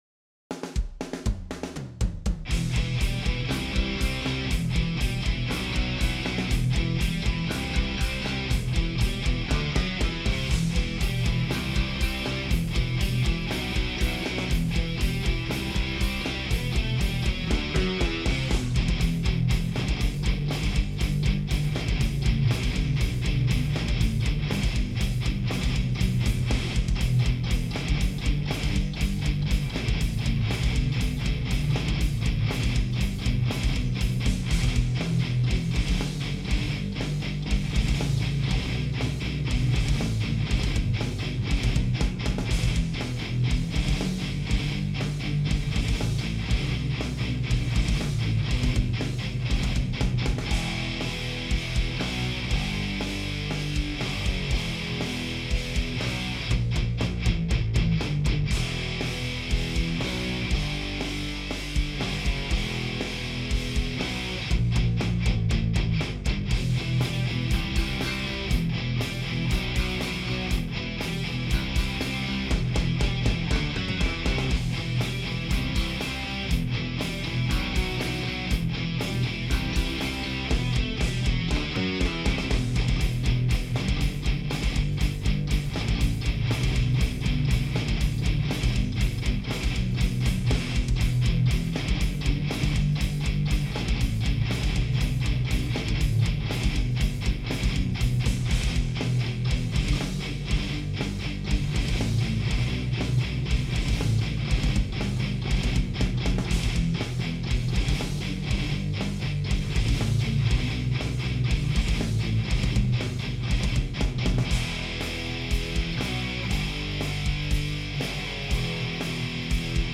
Added some reverb to snare and toms. A little EQ on master bus and guitars.
Then added some gelling reverb on the master that you can barely hear in headphones.
im hearing a slight build up in the low end id guess somewhere around 300hz or so. i think the snare could come up a bit and maybe lower the kick a tad. id probably hit it a little harder on the buss comp. these would be minor tweaks though, sounds good!